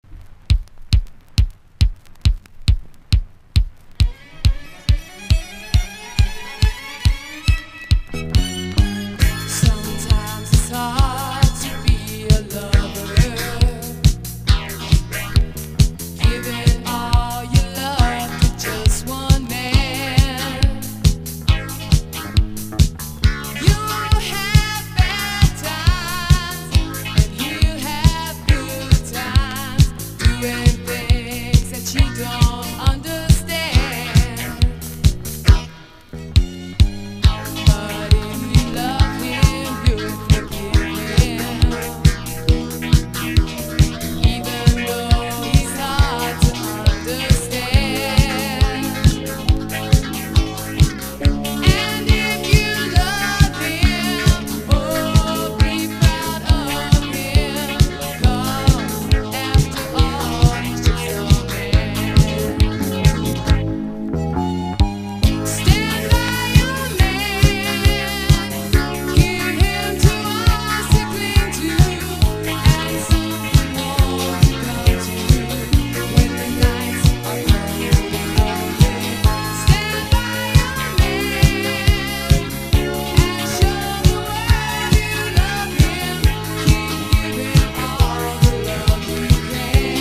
DISCO